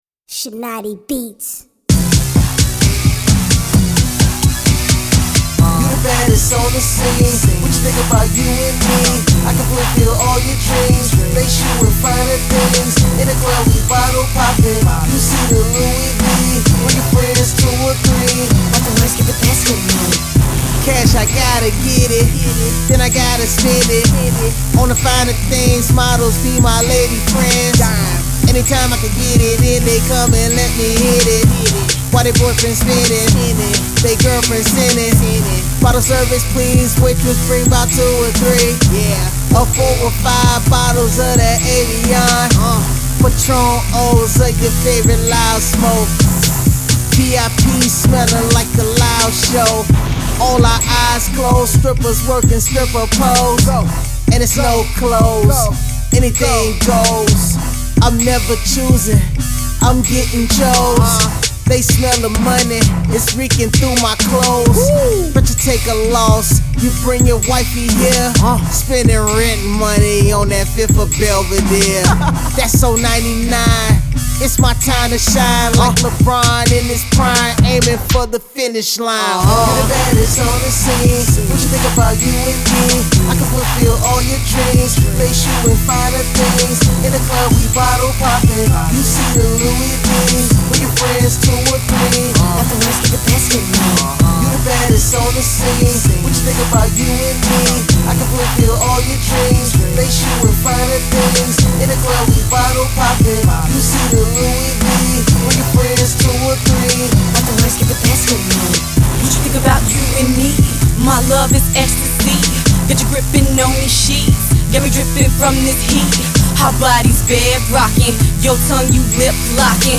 she sound old school,, foxy brown flow